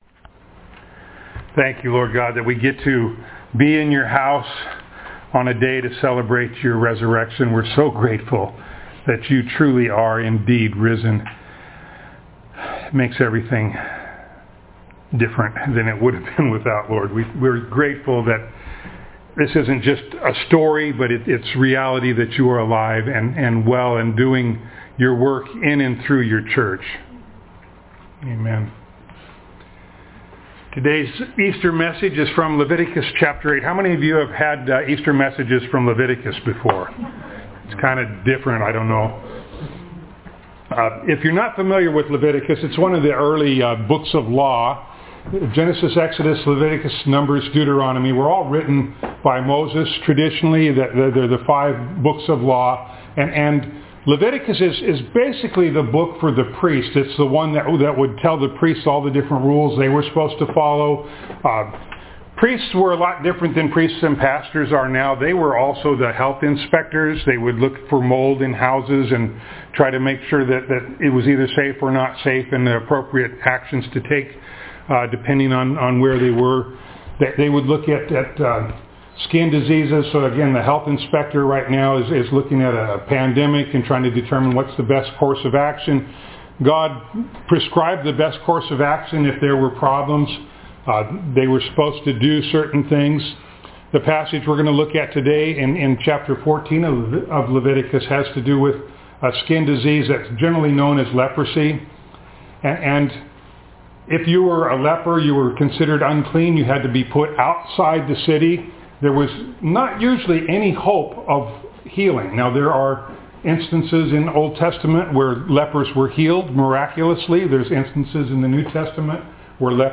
Ephesians Passage: Leviticus 14:1-20 Service Type: Sunday Morning Download Files Notes « Keep on Praying for ALL the Lord’s People Pray for Me